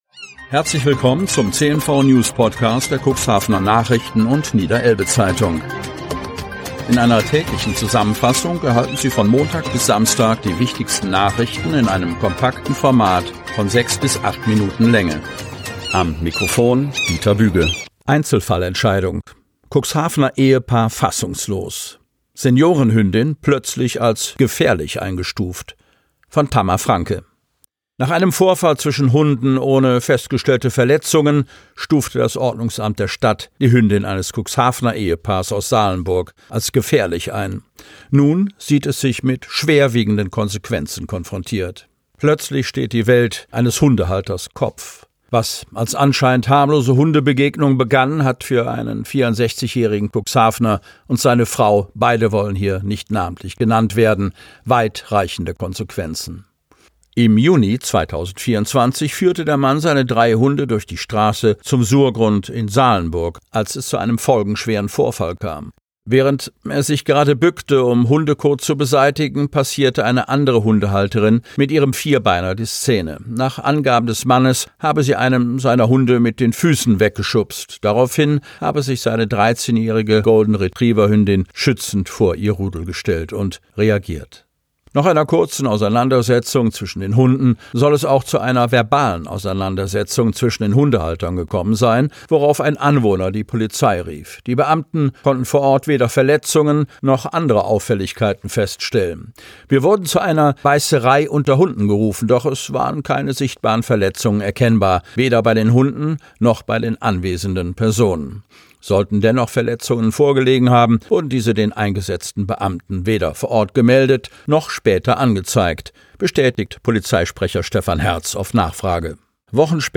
Ausgewählte News der Cuxhavener Nachrichten und Niederelbe-Zeitung am Vorabend zum Hören!